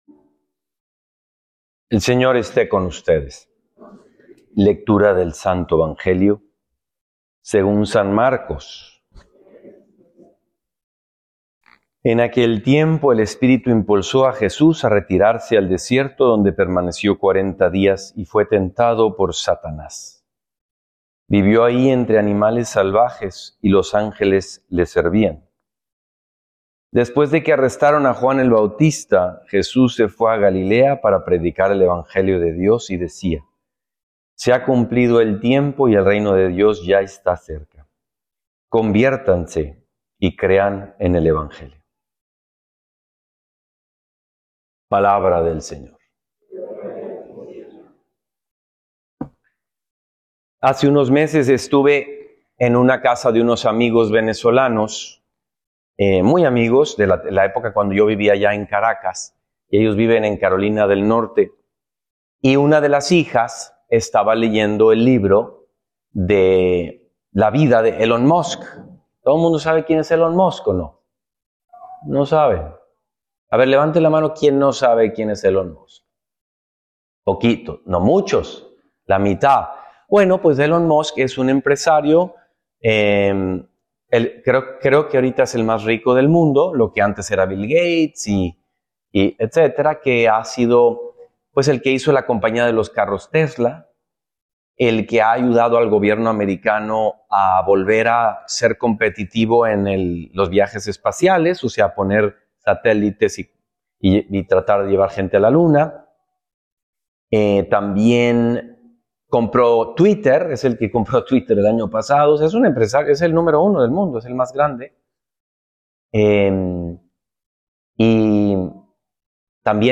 Listen to a Homily in Spanish